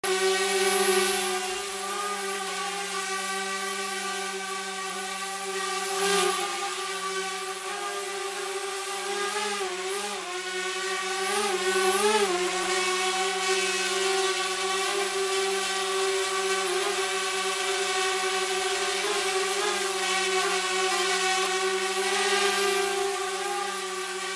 Flying Drone Sound Effect Free Download
Flying Drone